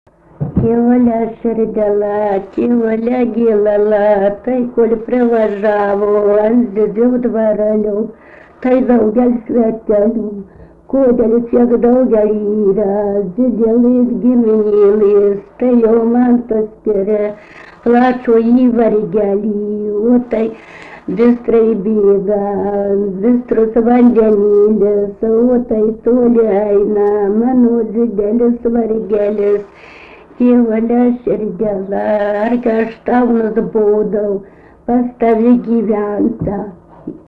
daina, vaikų
Erdvinė aprėptis Rudnia
Atlikimo pubūdis vokalinis